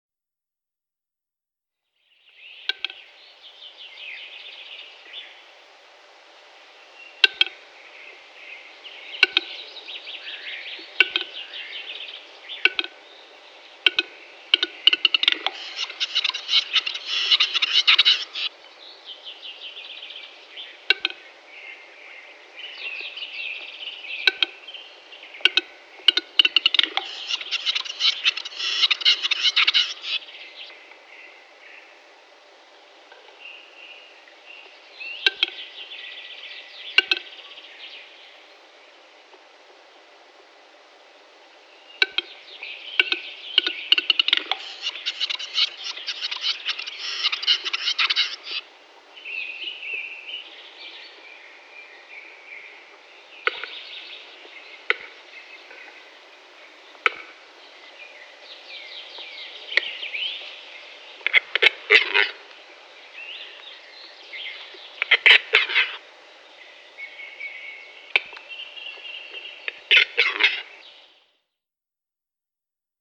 Il canto del Gallo Cedrone
Più che un uccello pare un trattore.....
GalloCedrone.wma